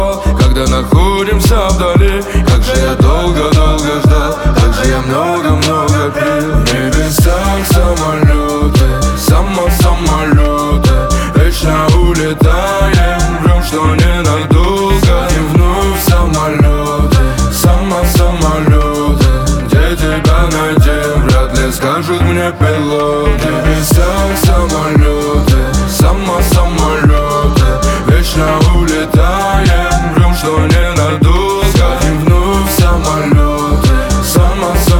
Жанр: Русские
# Поп